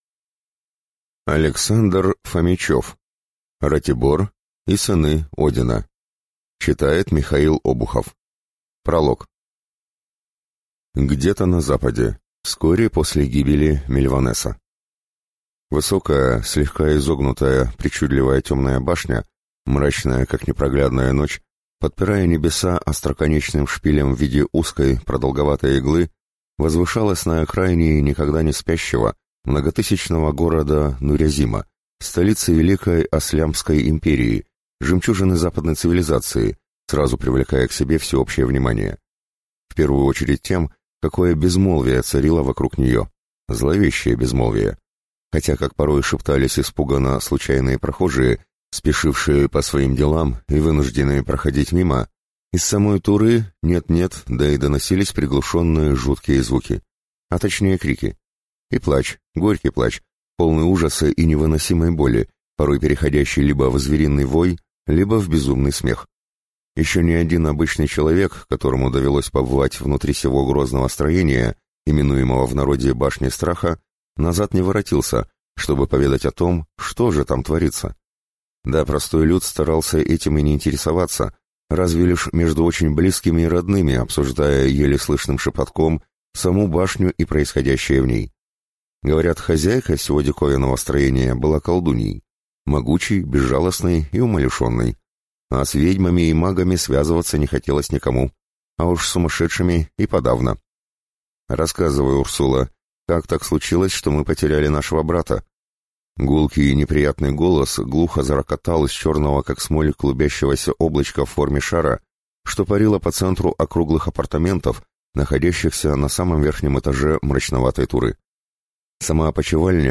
Аудиокнига Ратибор и сыны Одина | Библиотека аудиокниг